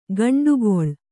♪ gaṇḍugoḷ